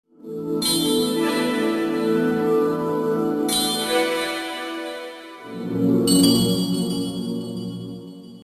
Рингтоны на СМС